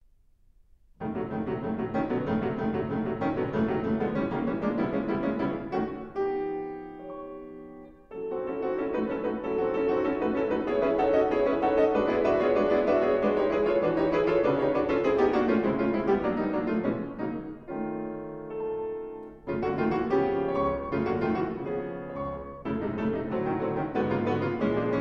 Prelude No. 13 in G flat major: Andante tranquillo